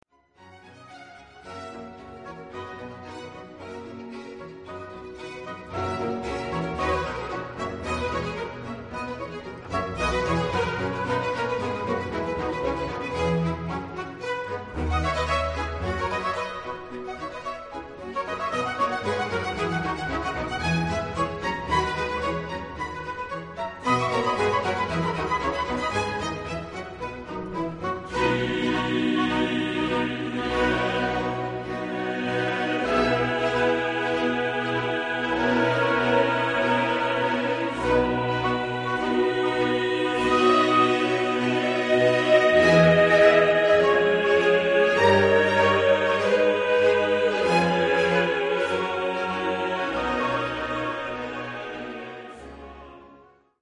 Genre-Style-Forme : Messe ; Sacré
Type de choeur : SATB  (4 voix mixtes )
Solistes : Sopran (1) / Alt (1) / Tenor (1) / Bass (1)  (4 soliste(s))
Instrumentation : Orchestre de chambre  (8 partie(s) instrumentale(s))
Instruments : Hautbois (2) ; Basson (1) ; Violon I ; Violon II ; Alto (1) ; Violoncelle (1) ; Orgue (1)
Tonalité : do majeur
interprété par Kammerchor Stuttgart dirigé par Frieder Bernius